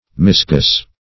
Misguess \Mis*guess"\, v. t. & i. To guess wrongly.